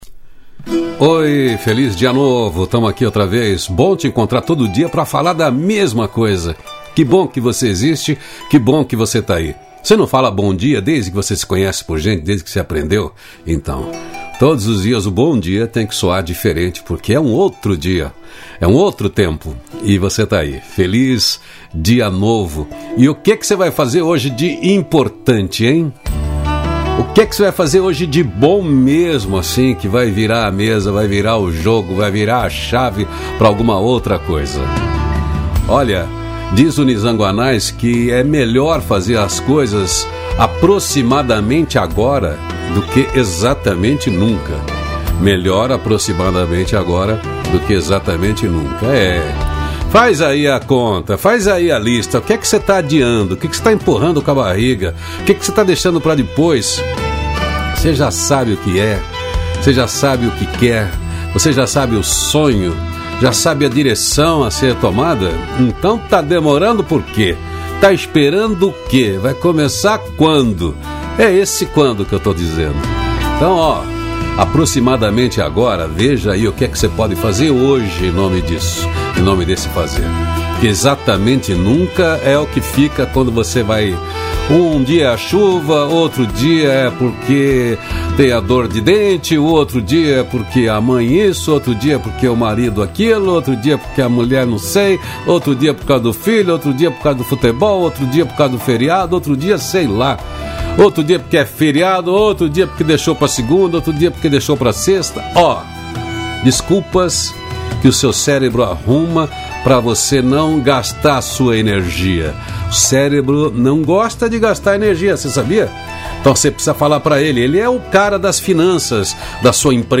CRÉDITOS: Produção e Edição: Onion Mídia Trilha Sonora: “Between the Shadows” Loreena Mckennitt